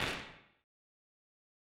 Claps